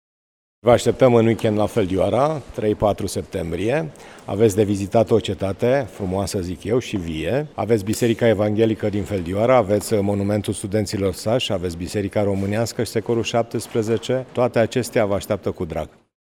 Cei care vor ajunge la finalul săptămânii în zona Feldioara au ocazia să viziteze și alte obiective turistice. Primarul comunei, Sorin Taus: